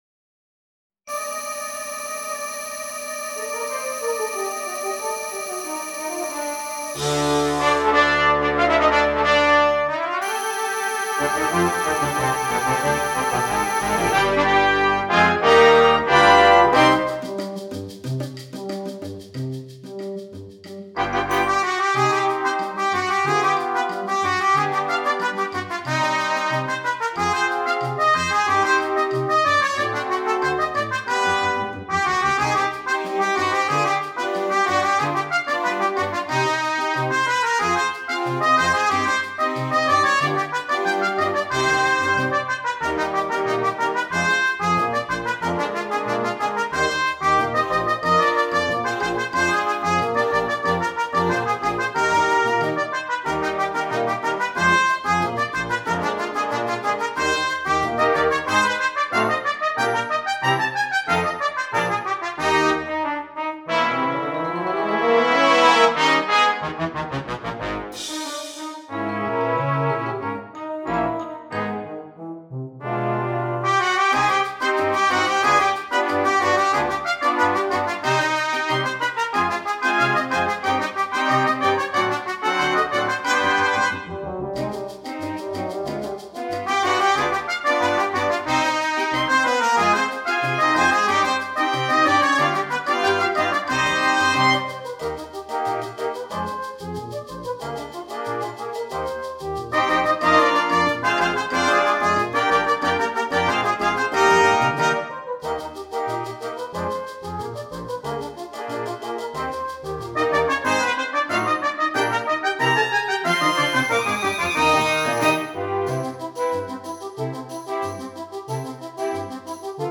Brass Choir (solo trumpet.4.4.3.1.1.perc)